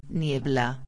26/12/2011 Niebla Névoa •\ [nie·bla] \• •\ Substantivo \• •\ Feminino \• Significado: Vapor aquoso que se levanta de lugares úmidos.
niebla.mp3